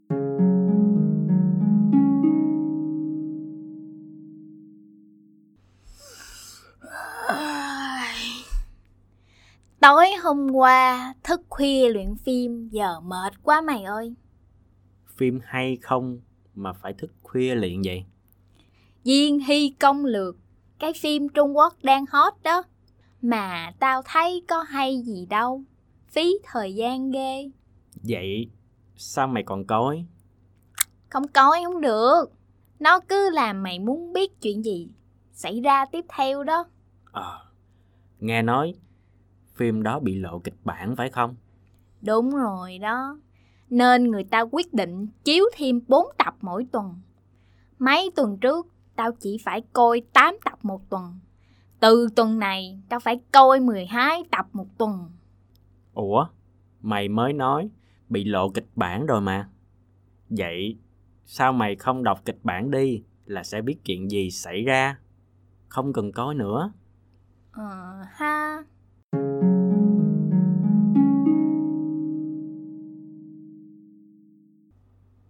I148_dialogue.mp3